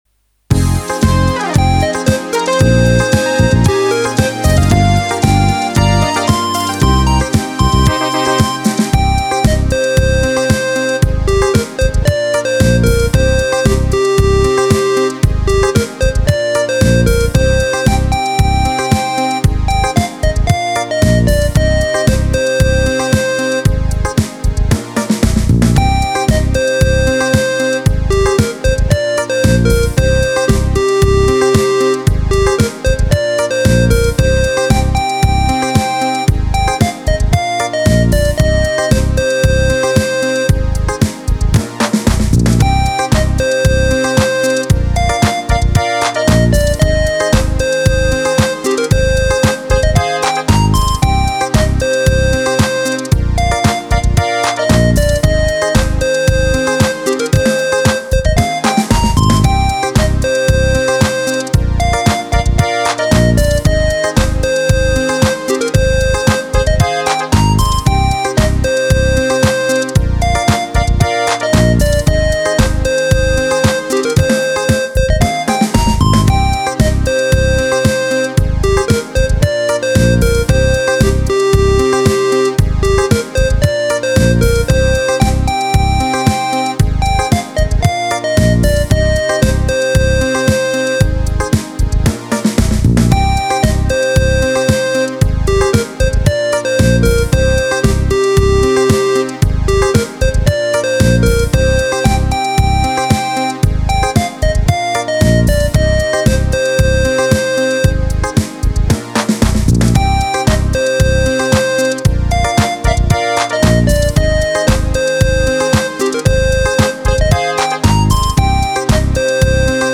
8 Beat
Deze keer kies ik gewoon het volgende ritme in de reeks: 002 DiscoPop, aan 114 BPM.
Alles viel mooi op zijn plaats: de groove zit goed, de opbouw werkt, en het ritme zelf is gewoon erg prettig om mee te spelen. Jammer genoeg is er één instrument dat duidelijk te luid staat en voortdurend door de mix snijdt, wat wat afbreuk doet aan het geheel.